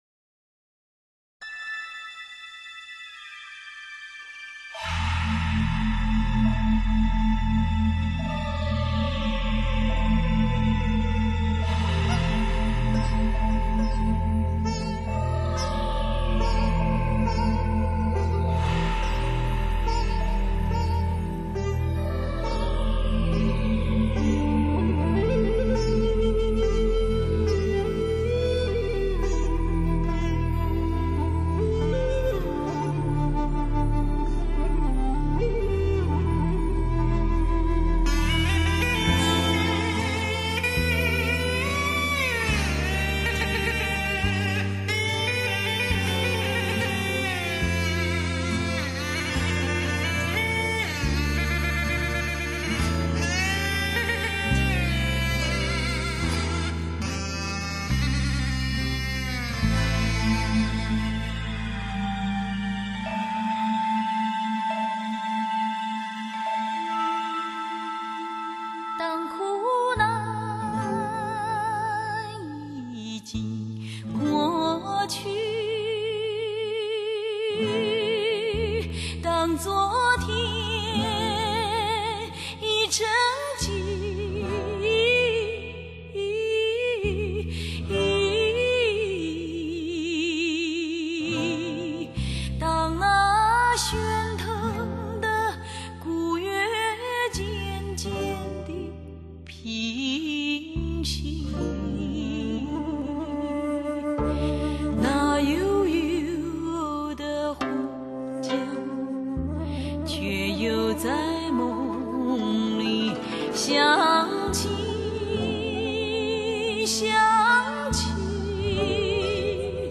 此碟音效极好。
七个段落组成，整部作品气势磅礴，演唱、录音俱佳。